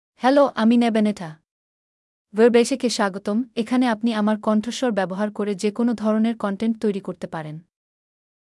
FemaleBangla (Bangladesh)
Nabanita — Female Bangla AI voice
Nabanita is a female AI voice for Bangla (Bangladesh).
Voice sample
Female